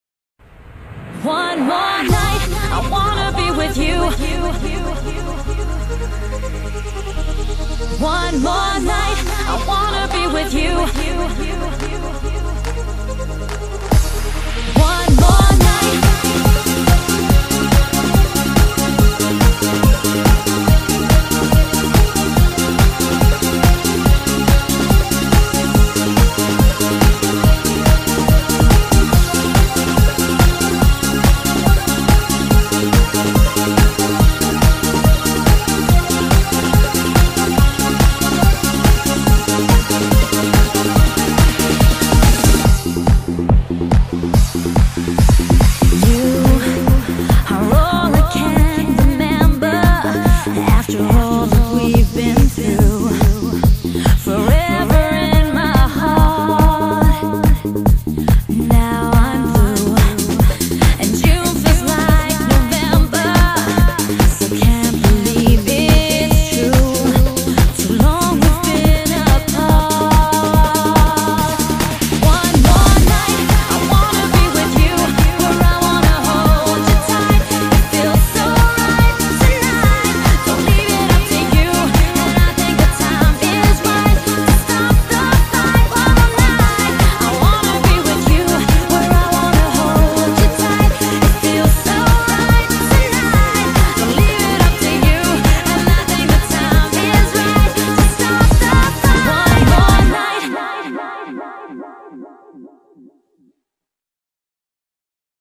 BPM142
Audio QualityPerfect (High Quality)
This is a Eurodance remix